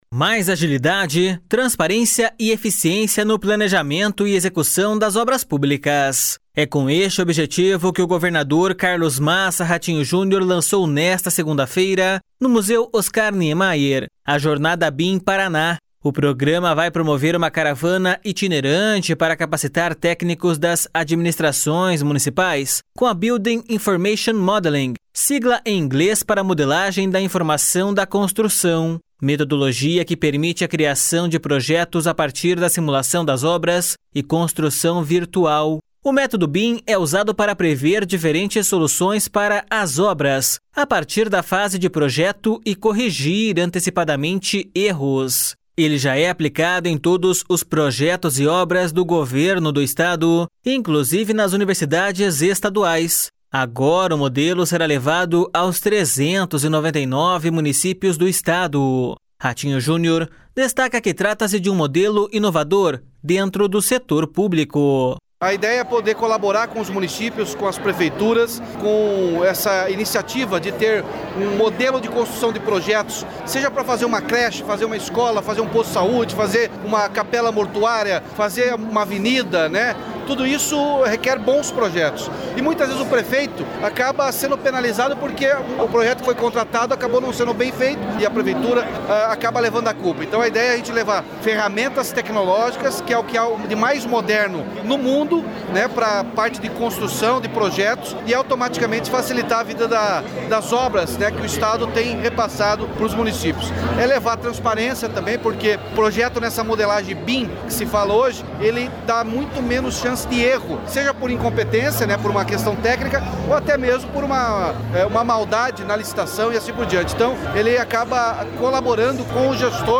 Ratinho Junior destaca que trata-se de um modelo inovador dentro do setor público.// SONORA RATINHO JUNIOR.//
Segundo o secretário estadual de Infraestrutura e Logística, Sandro Alex, o Paraná foi o primeiro estado do Brasil a adotar a metodologia BIM e a estendê-la em um programa estadual para todos os municípios.// SONORA SANDRO ALEX.//